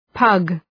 Προφορά
{pʌg}